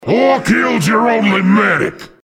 Extracted with GCFScape and WinRAR from the vsh_outburst.bsp.
This is an audio clip from the game Team Fortress 2 .